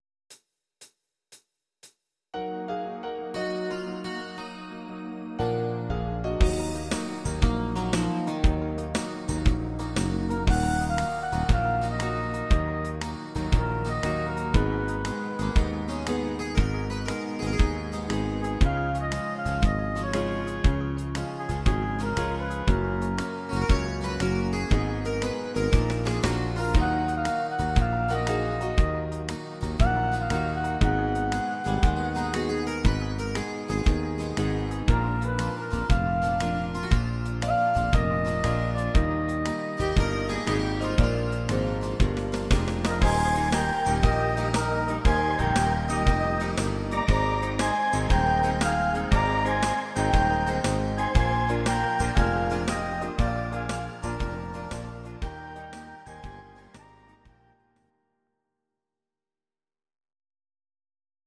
These are MP3 versions of our MIDI file catalogue.
Your-Mix: Volkstï¿½mlich (1262)